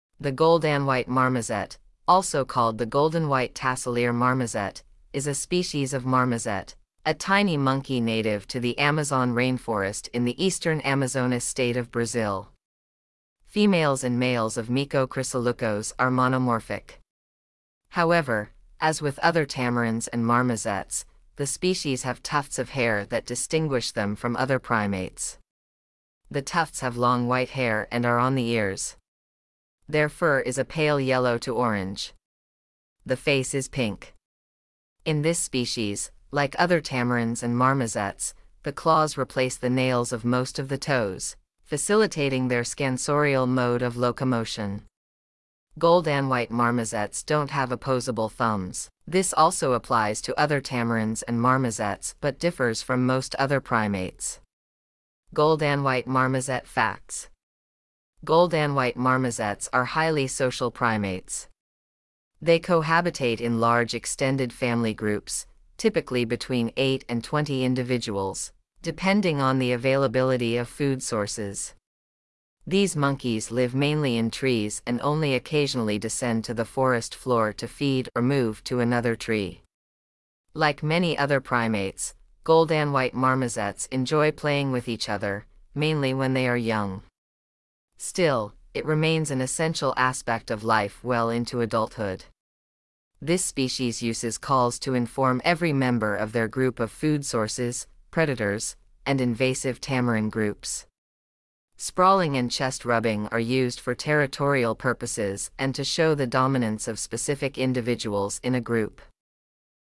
gold-and-white-marmoset.mp3